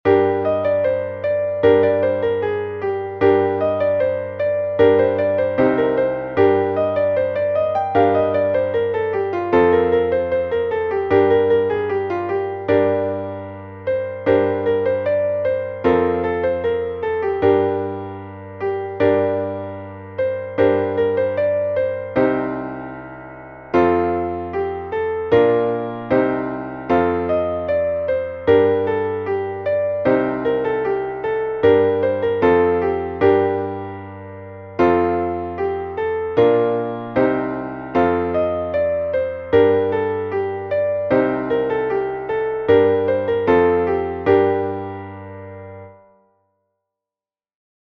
μελωδία και συγχορδίες με εισαγωγή, Gmin